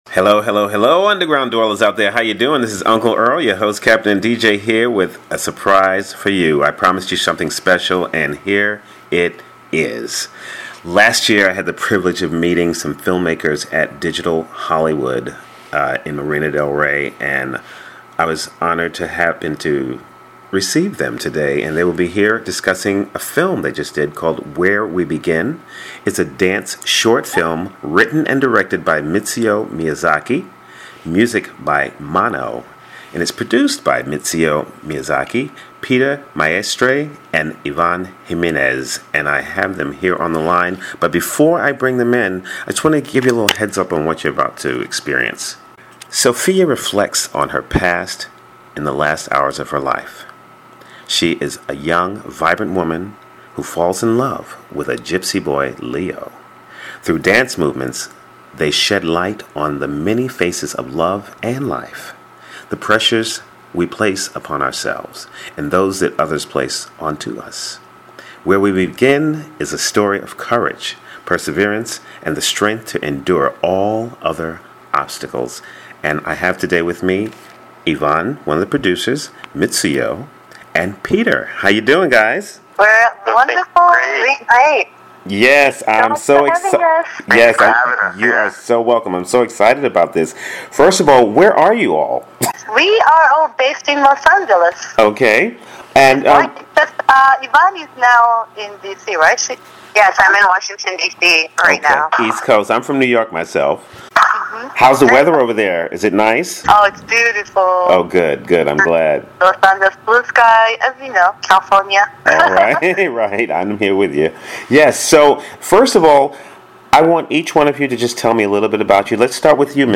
Where We Begin is a story of courage, perseverance and the strength to endure all other obstacles. Here is the radio interview done by The Ultimate Underground